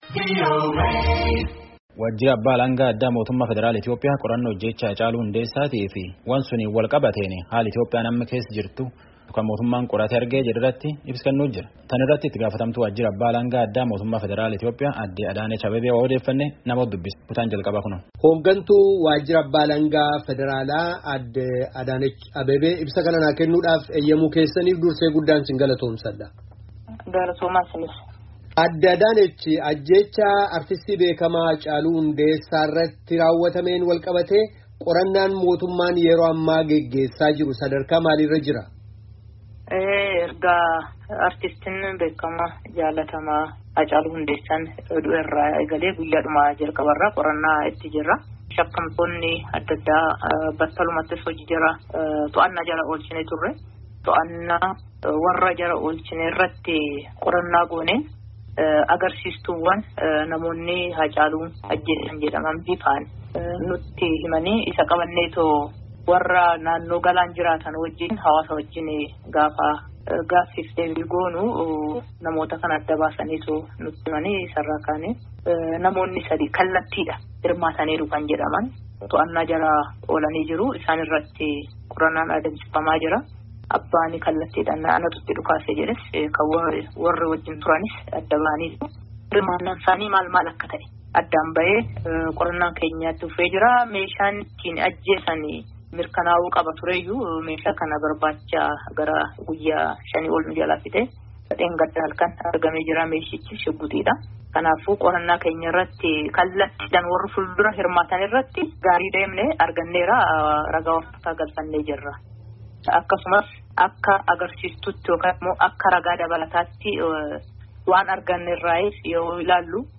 Ajjeechaa Artistii beekamaa, Haacaaluu Hundeessaa irratti raaw’atameen wal-qabatee, qorannaan mootummaan yeroo ammaa geggeessamaa jiruu fi dhimmoota ka biroo yeroo ammaa Itiyoophiyaa keessatti mul’atan irratti, hooggantuu waajira Abbaa-alangaa muummichaa, ka mootummaa federaalaa Itiyoophiyaa – Aadde Adaanech Abeebee waliin gaaffii fi deebii geggeessinee jira.